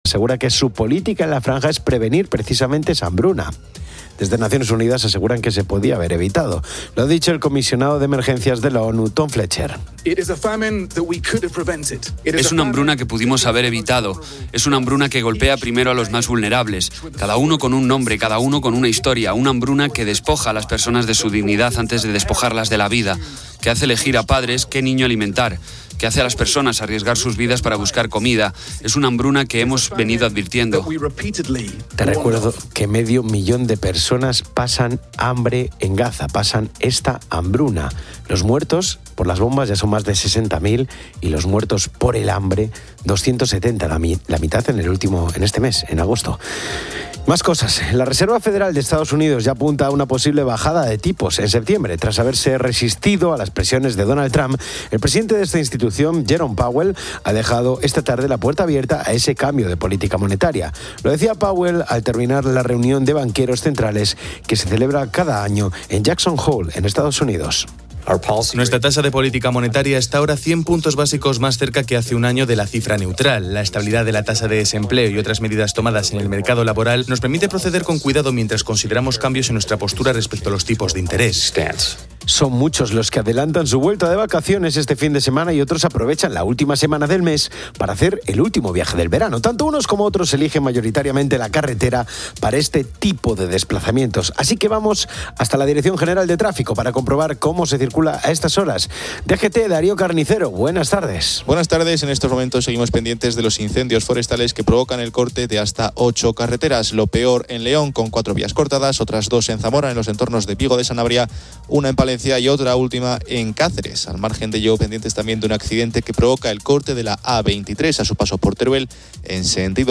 El audio cubre noticias y una entrevista al grupo Las Migas.